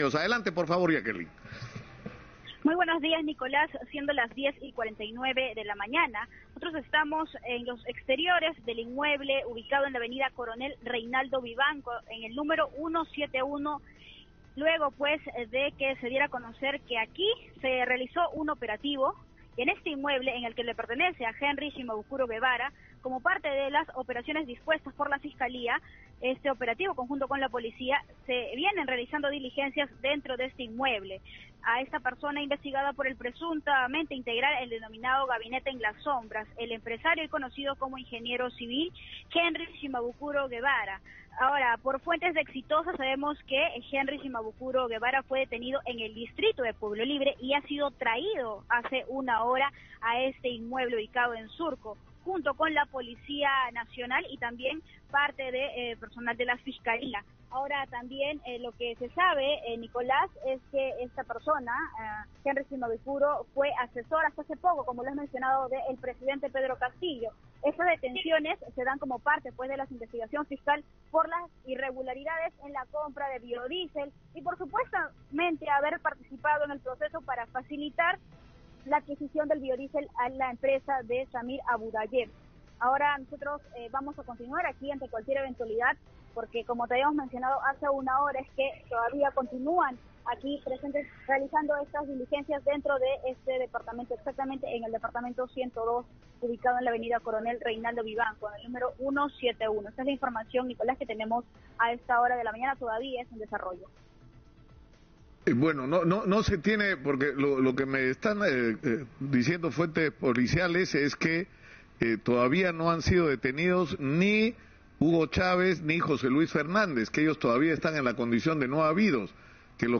Desde Surco